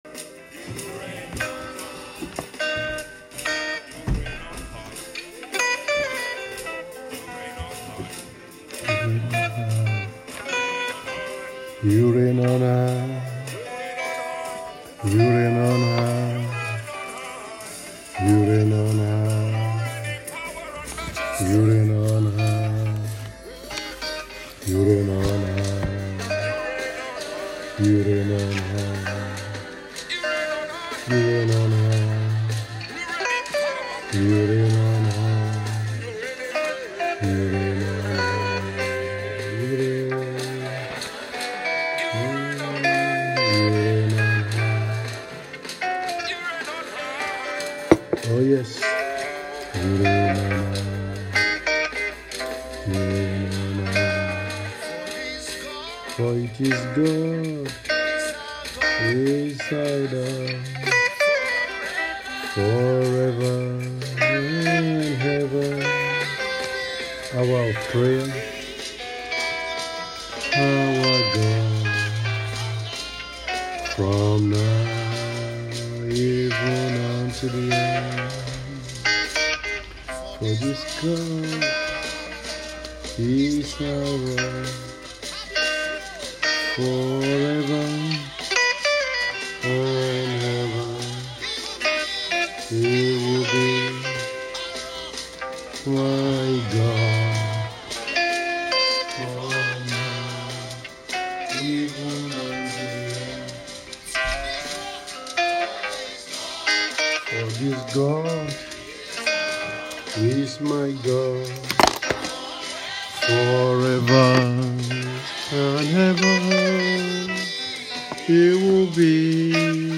Praying for Our Daughters Saturday Hour of Prayer